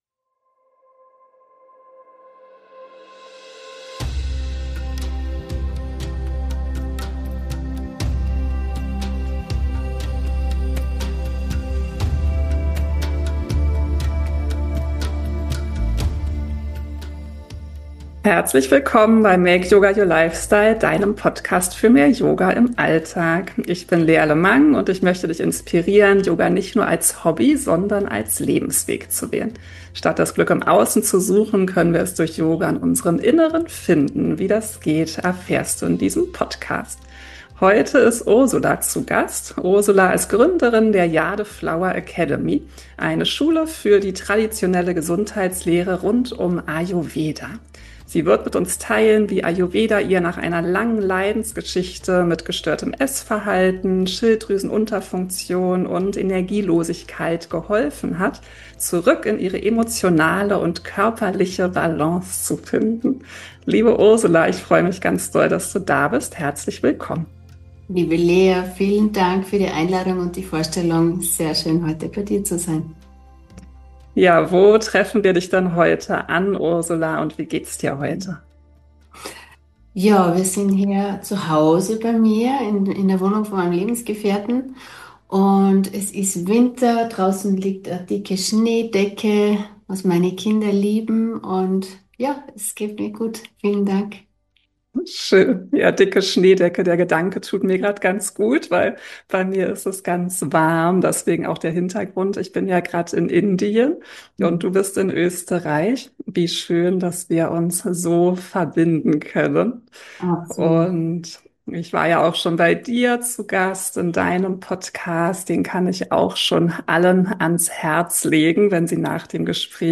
Erfahre, wie Ayurveda ganzheitlich wirken und dein Leben vollkommen transformieren kann. Viel Freude bei dem Gespräch!